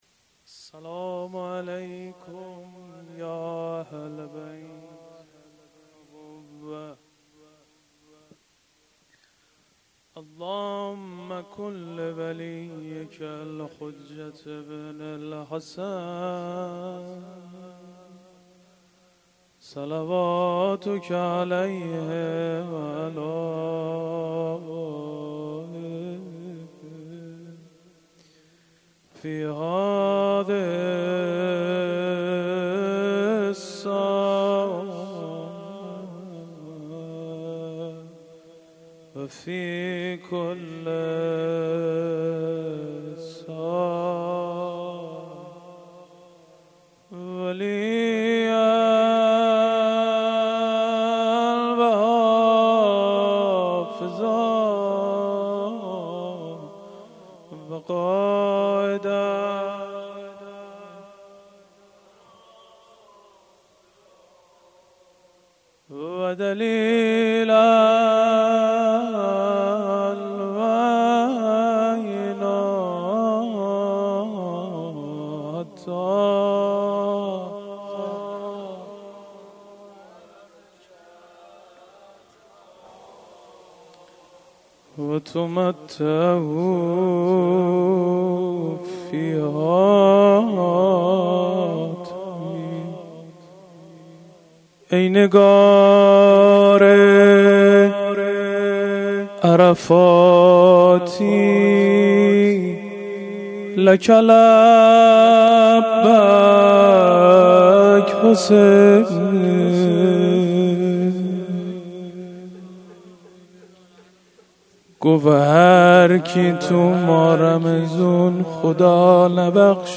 روضه
ایام شهادت حضرت مسلم علیه السلام